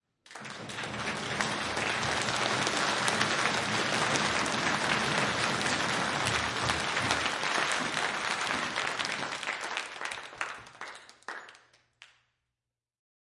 四声道的观众/人群声音 " M长的掌声和欢呼声 交错进行2
描述：这些是罐装人群的声音，为戏剧制作录制。这些是以四边形录制的，设计由四个扬声器播放，一个靠近房间的每个角落。我们和一小群人一起制作了它们，每次反应记录了20层左右，让小组在房间里移动。如果声音需要背靠背播放，如连续3轮掌声，那么这些层的一些替代布置，及时掠过，进行一些变化，这将有助于逼真。这些是“交错”的文件。 这些是在中型大厅录制的，前左右声道有AKG C414，后左右声道有Neumann KM184。
标签： 全场 欢呼声 喧闹 鼓掌 人群 掌声